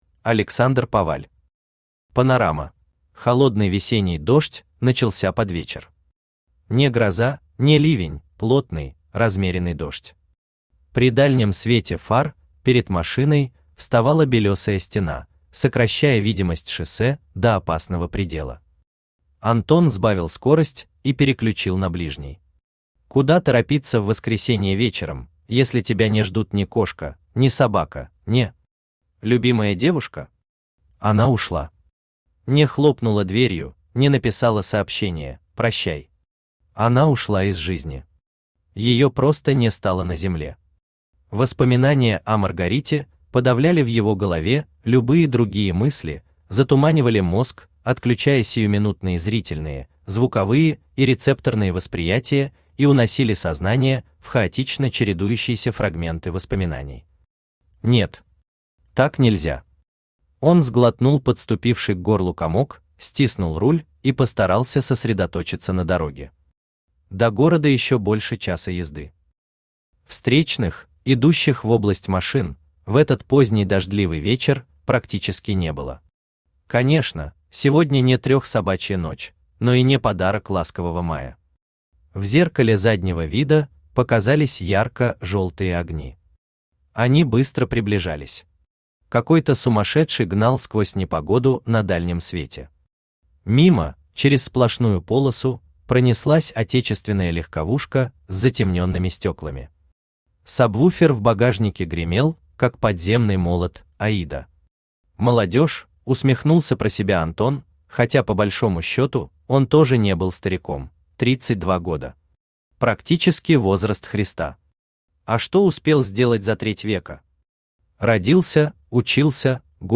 Аудиокнига Панорама | Библиотека аудиокниг
Aудиокнига Панорама Автор Александр Паваль Читает аудиокнигу Авточтец ЛитРес.